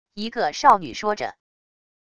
一个少女说着wav音频